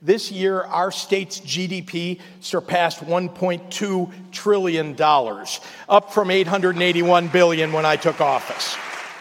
The governor delivered his State of the State and budget address at the Illinois Capitol on Wednesday.